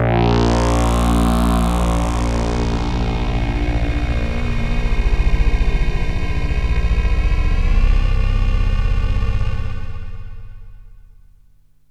AMBIENT ATMOSPHERES-3 0001.wav